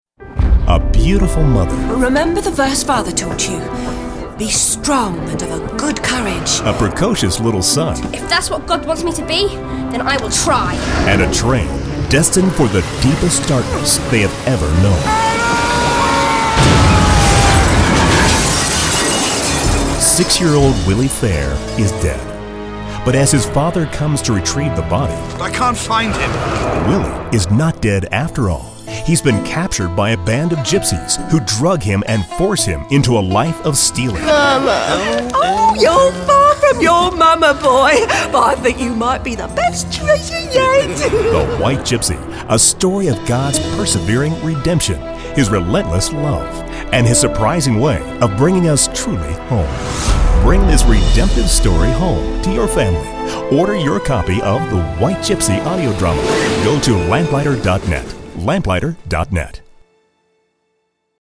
White Gypsy – Dramatic Audio MP3 Download
Positively Encouraging Listen The eerie scene setting works beautifully to lead into a delightful story of restoration through providence along a path of misfortune and benevolence.
Great acting, great story, well-developed characters, great sound effects.
The-White-Gypsy-60s_PROMO.mp3